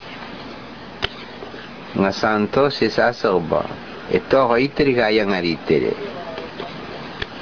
telling this true story from 1972 in Tobian